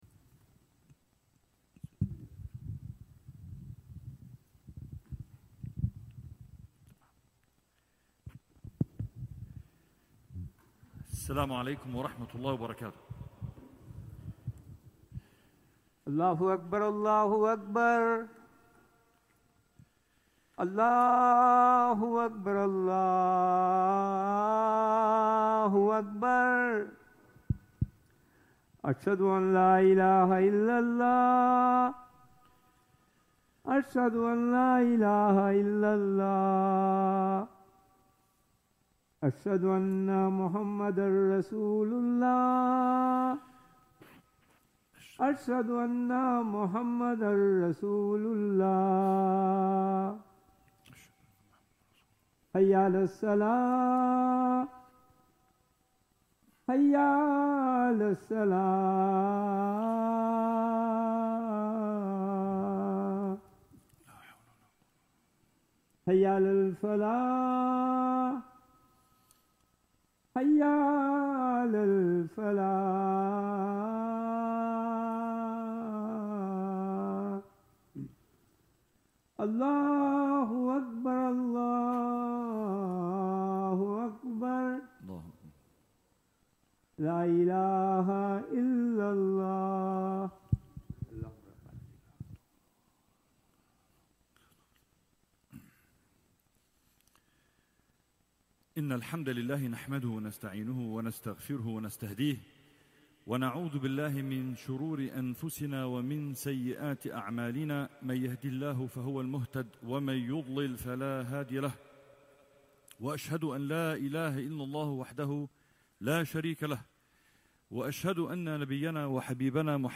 Friday Khutbah - "Welcome Ramadan!"